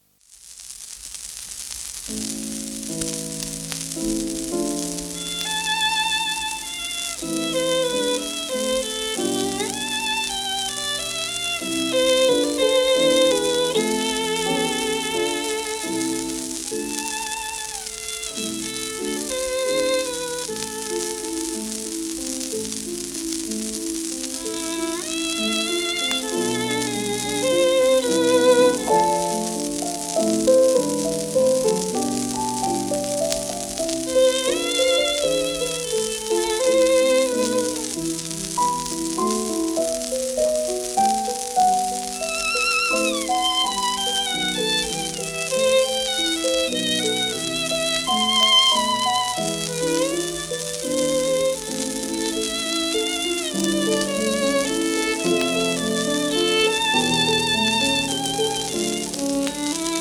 w/ピアノ
シェルマン アートワークスのSPレコード